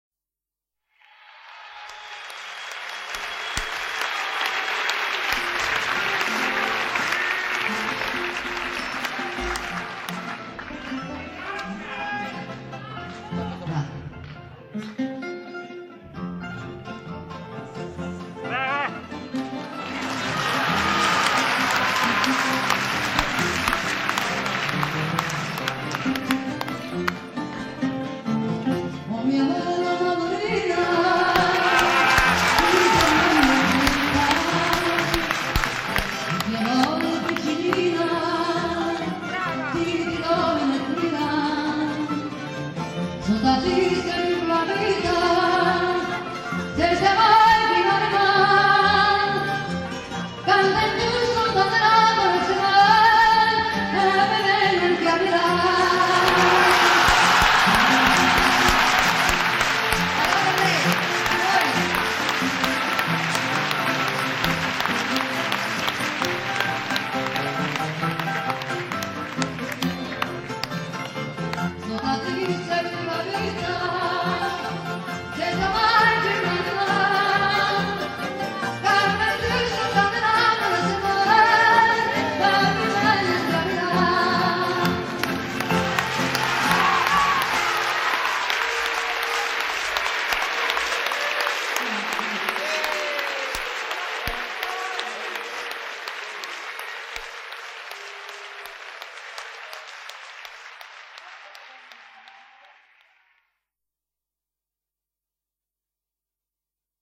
guitare portugaise
guitare classique
basse acoustique.